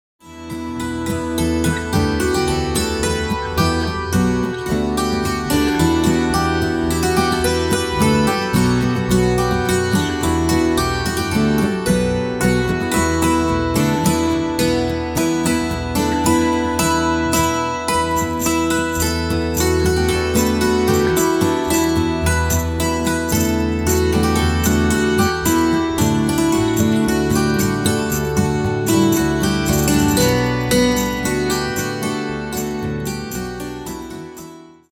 Singing with enchanting harmonies
Celtic Harp, Renaissance Lute, Hammered Dulcimer, Recorders and more in their program of ancient carols and original instrumental music for the holiday season.
huron_carol_medley.mp3